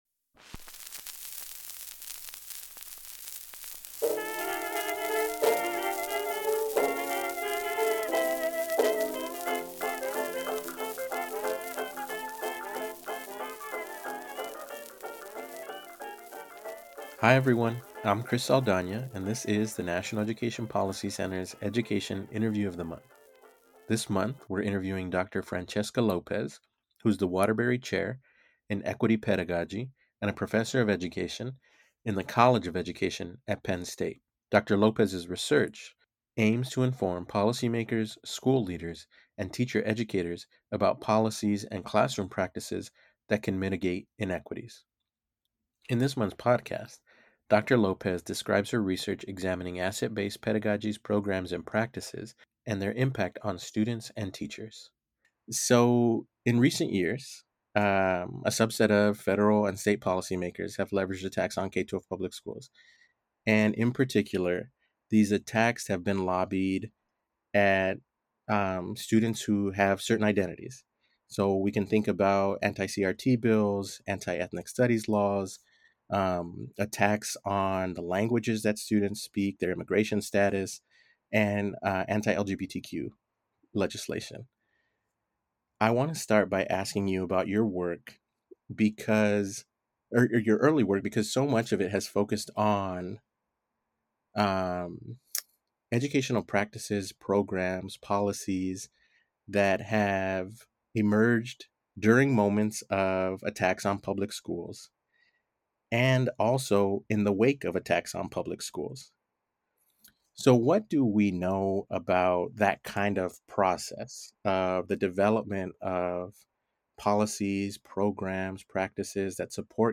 Education Interview